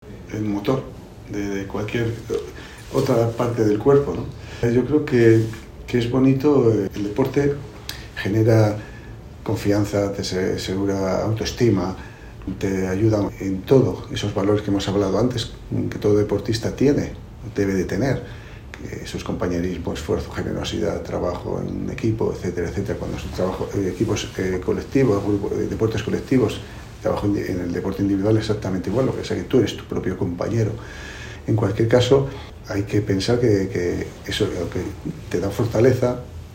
De la Fuente durante su intervención acompañado de Fernando RiañoHan participado en el último Comité de Coordinación General 2024 del Grupo Social ONCE, centrado en la salud mental